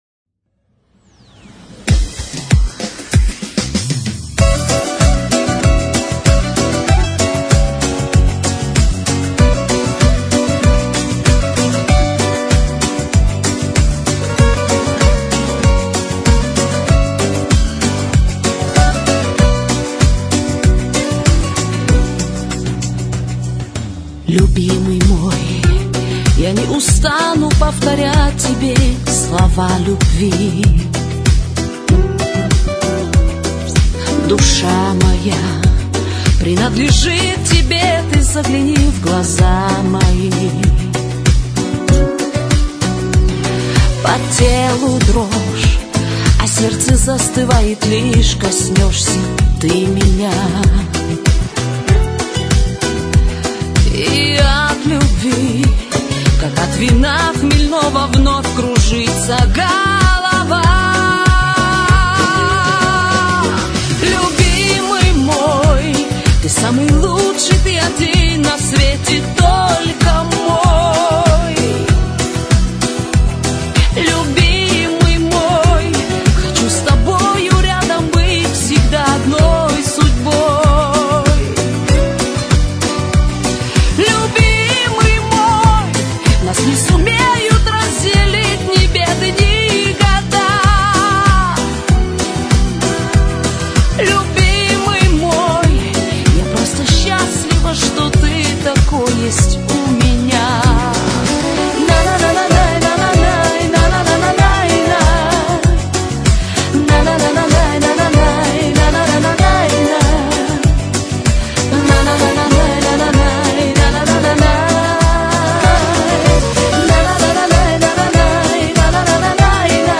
Всі мінусовки жанру Pop-Folk
Плюсовий запис